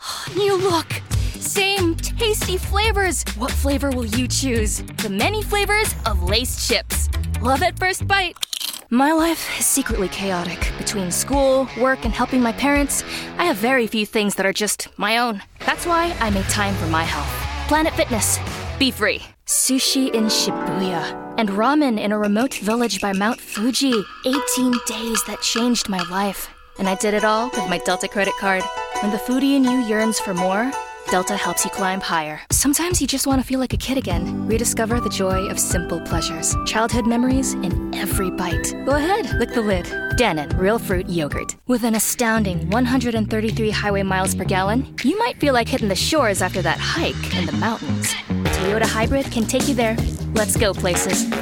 Commercial Demo
My vocal quality is first and foremost youthful, earnest, grounded and natural but I do have so many more capabilities under my belt.